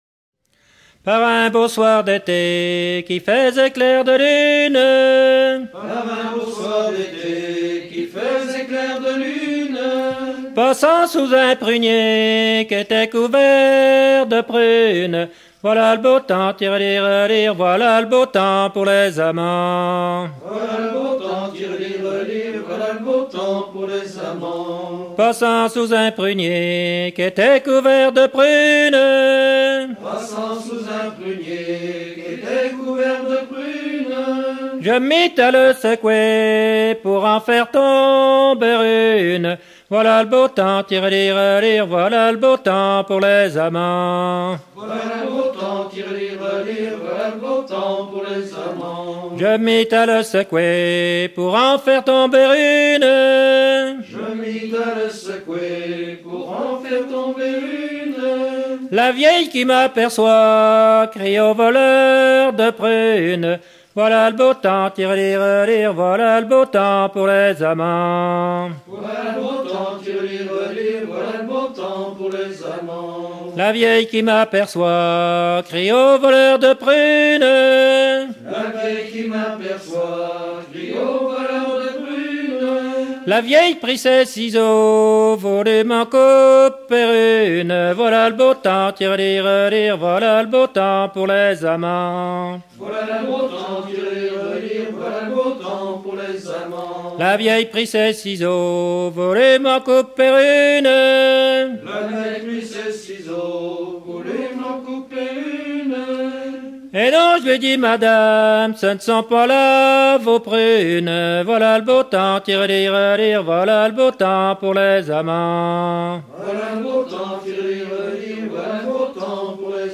Bocage vendéen
Genre laisse